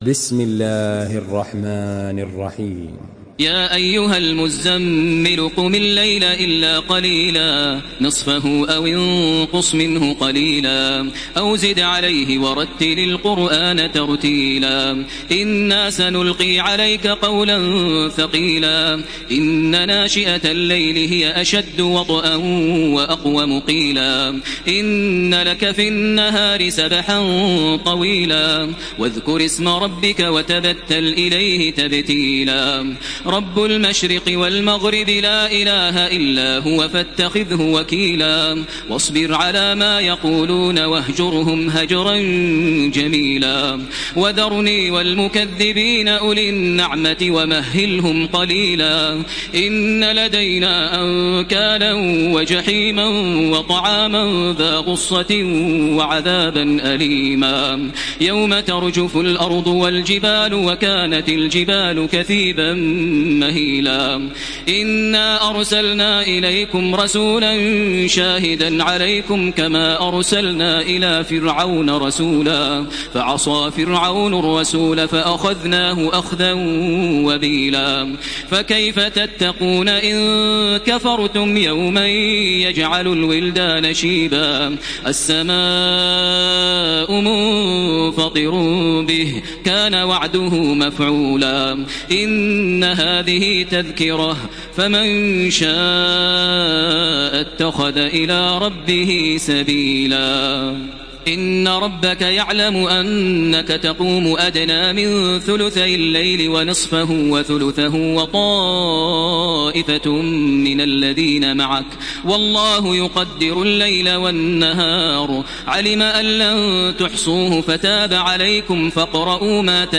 تحميل سورة المزمل بصوت تراويح الحرم المكي 1433